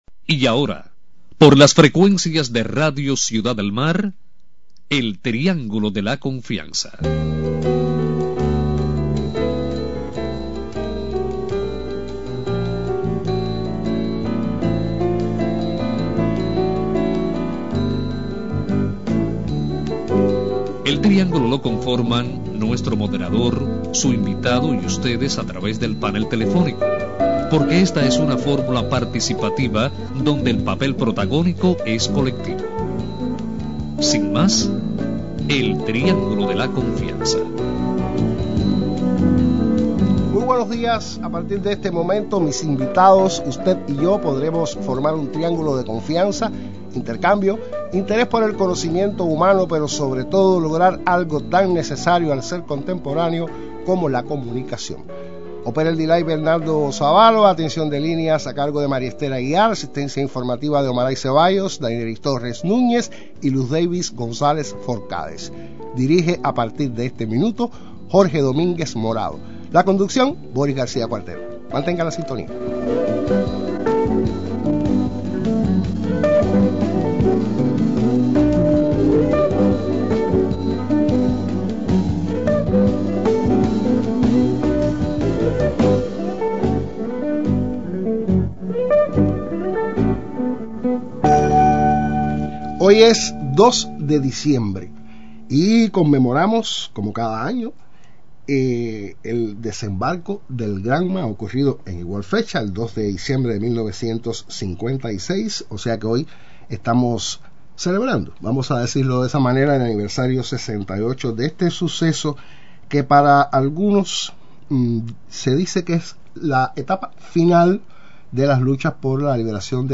Miembros de la Asociación de Combatientes de la Revolución Cubana participan en el programa El triángulo de la confianza dedicado al aniversario 68 del desembarco del yate Granma y Dìa de las Fuerzas Armadas Revolucionarias.